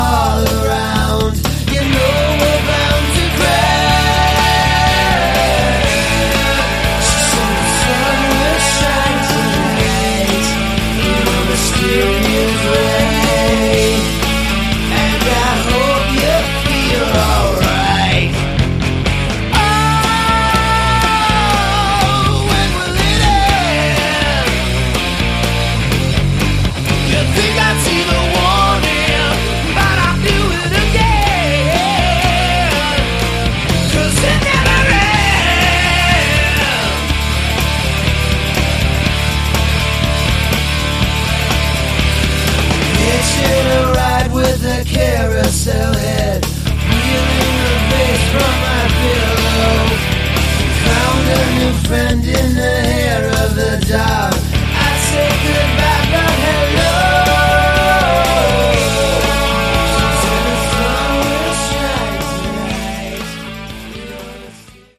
Category: Hard rock/Glam
...but with a horrible sound quality.